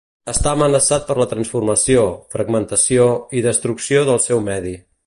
[fɾəɡ.mən.tə.siˈo]